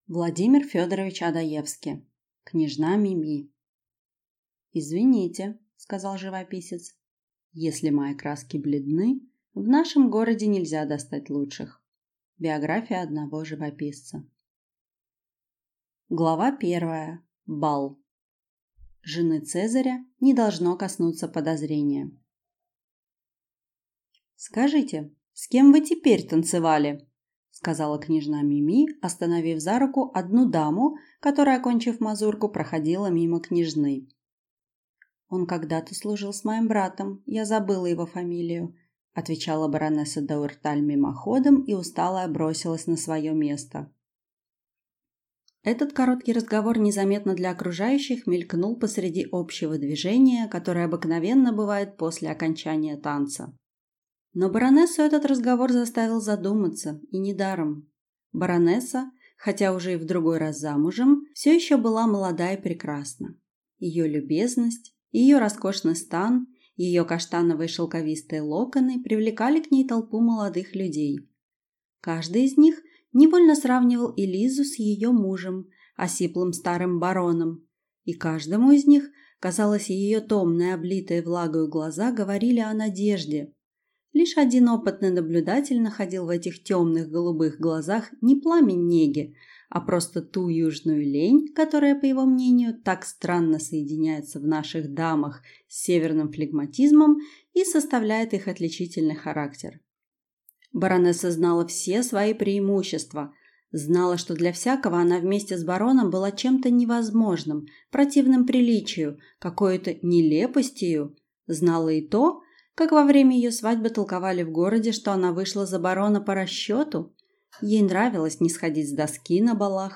Аудиокнига Княжна Мими | Библиотека аудиокниг